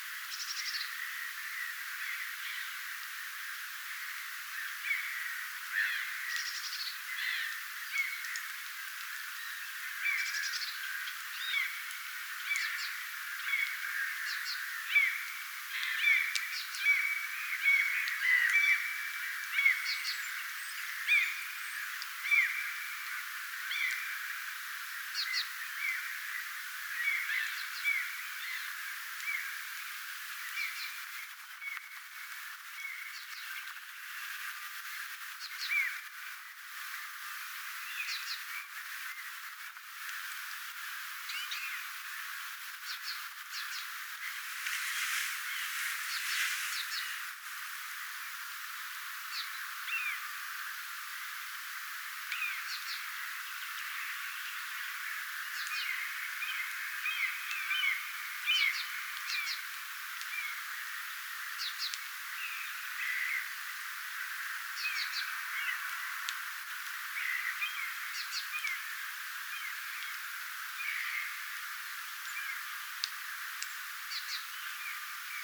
onko_tuo_sinitiainen_parempi_aanitys_tilanteesta.mp3